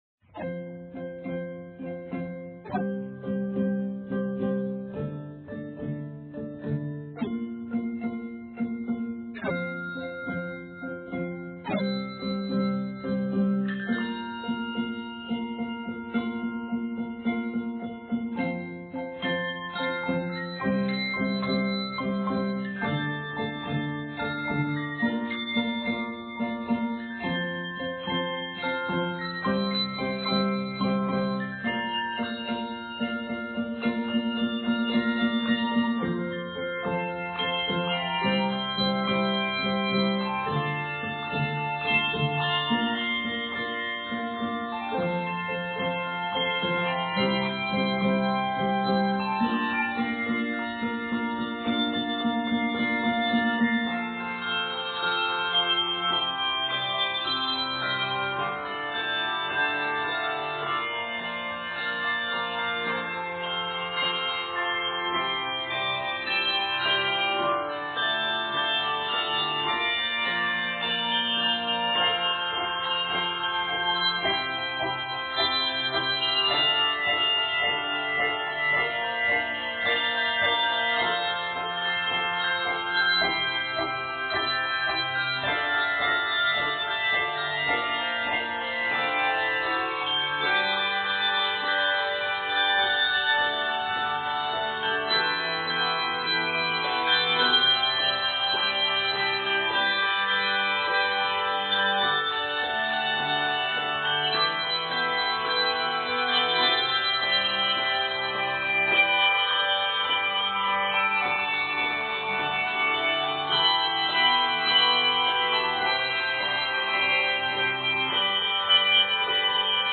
Driving rhythmic patterns dominate
3-5 octave arrangement
praise and worship song